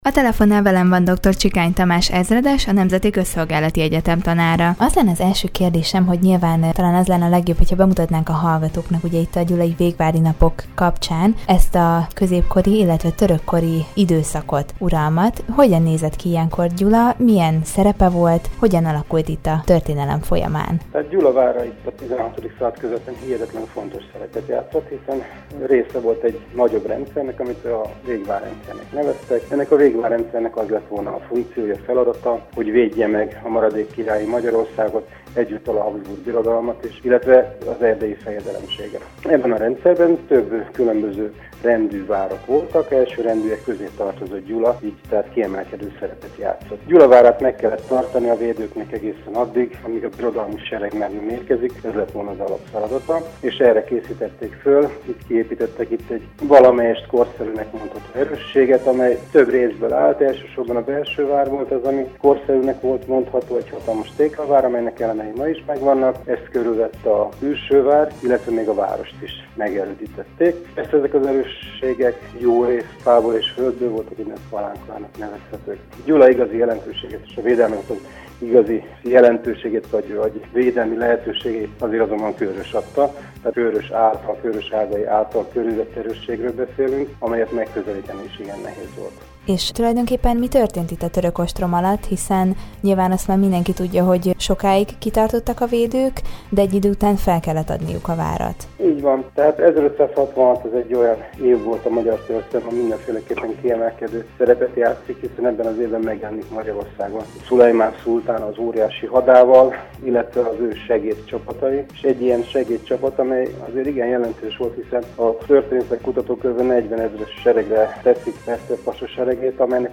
a Közszolgálati Egyetem tanárával beszélgetett tudósítónk a közelgő Végvári napokhoz kapcsolódóan a gyulai vár török időkben betöltött szerepéről.